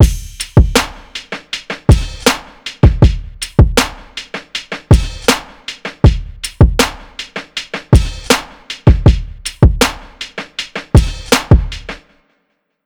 Sum It Up Loop.wav